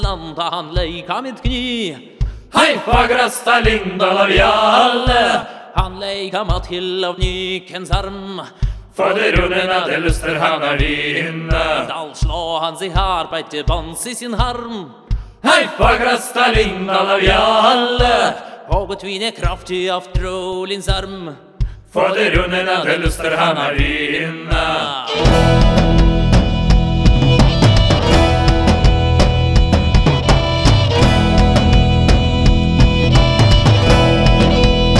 # Traditional Celtic